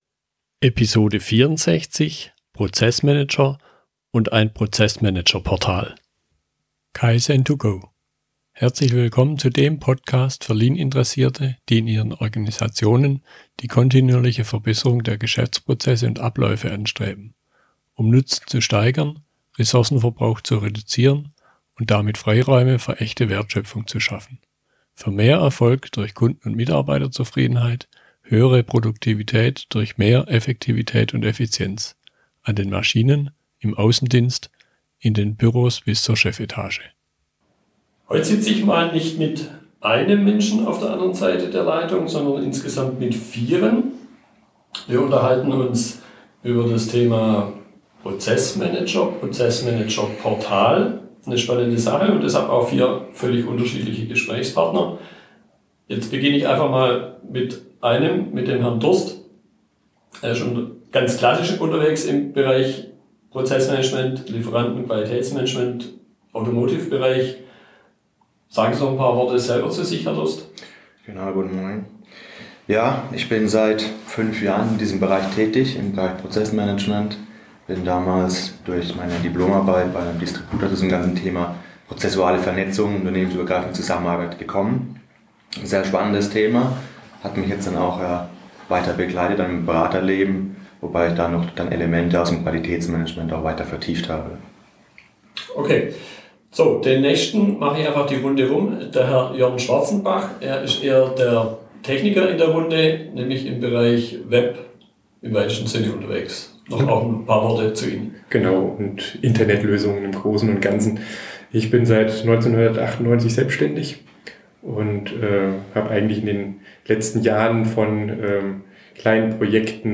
Im Gespräch mit dem Team des Prozessmanager-Portals